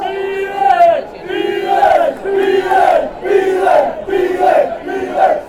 A FTFC soccer chant.